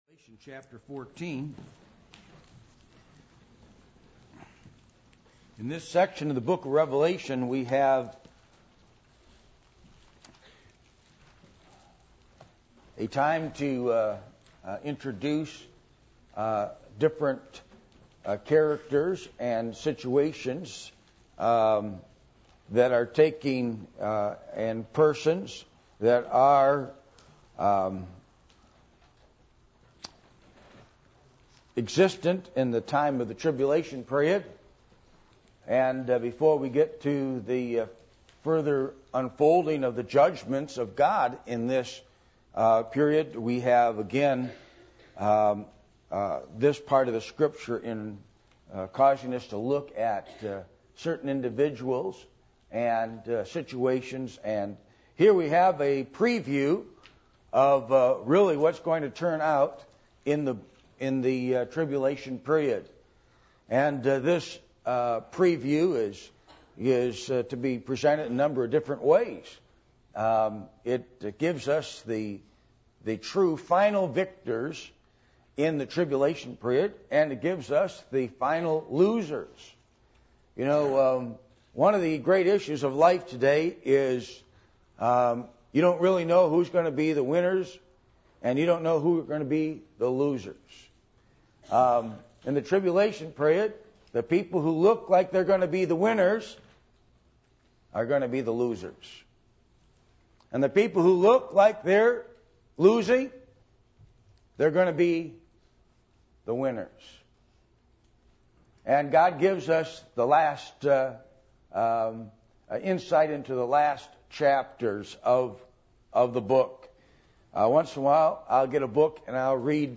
Passage: Revelation 14:1-20 Service Type: Sunday Morning